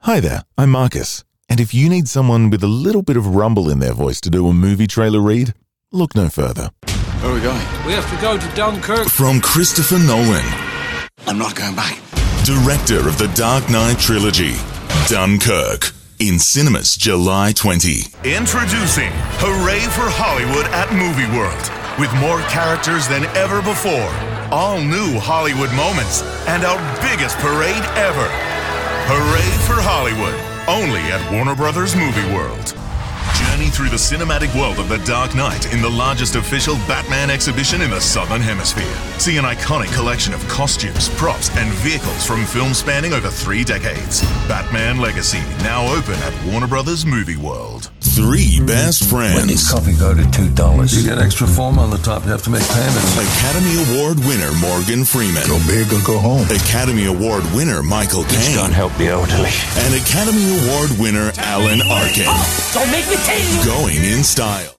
Male
English (Australian)
Adult (30-50)
Sincere? Commanding? Familiar? Caring? Conversational? Playful? Consider it done.
Movie Trailer Demo